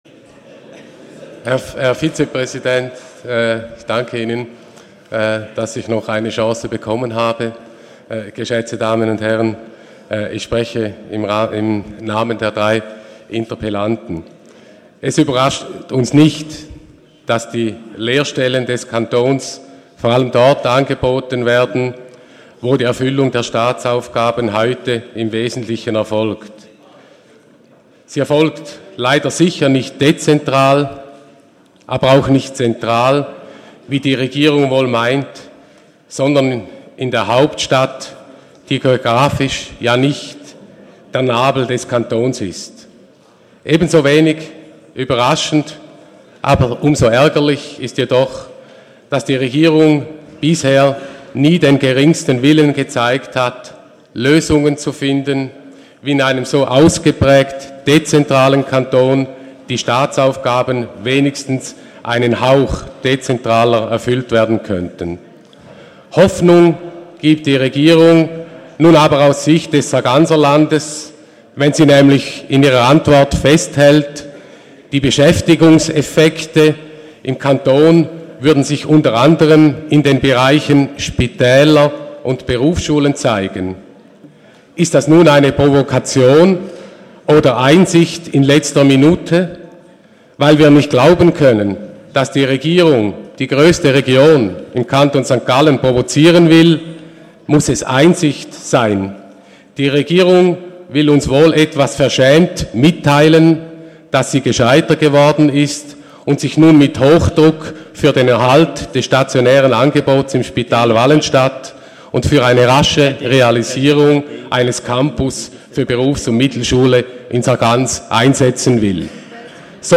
26.11.2019Wortmeldung
Sprecher: Zoller-Quarten
Session des Kantonsrates vom 25. bis 27. November 2019